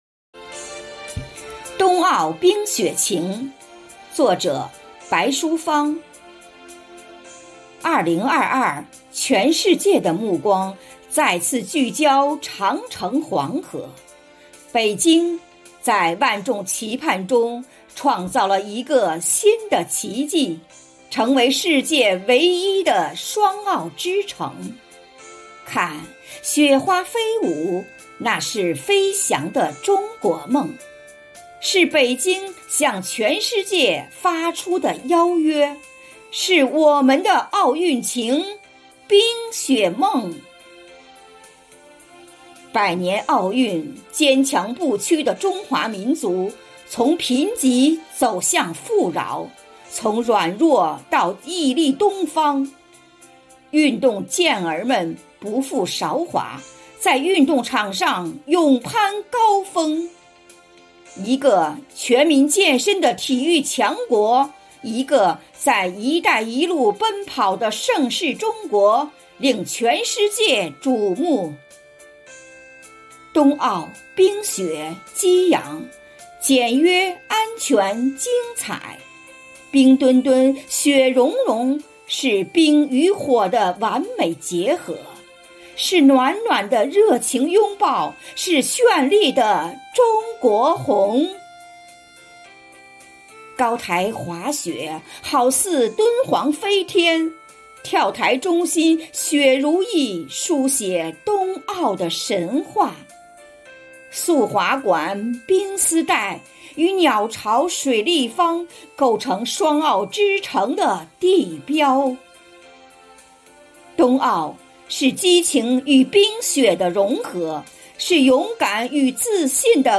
冰墩墩虽然下班了，生活好课堂朗读志愿者的朗读祝福却没停！